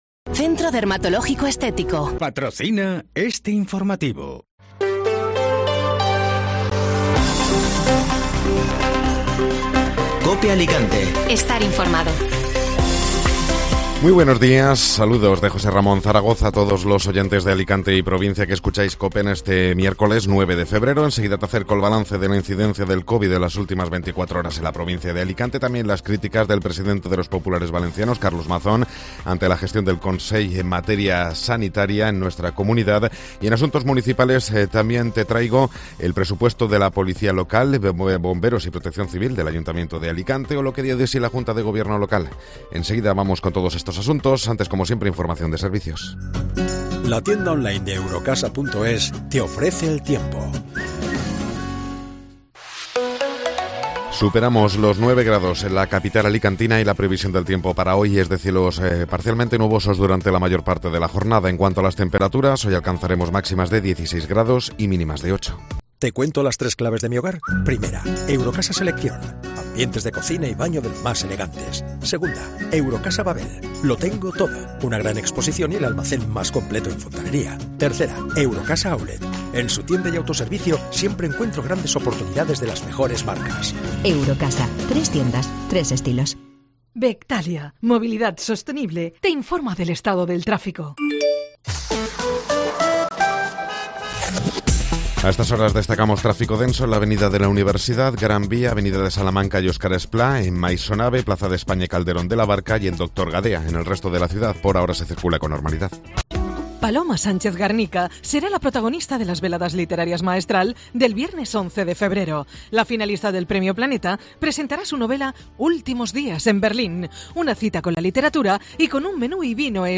Informativo Matinal (Miércoles 9 de Febrero)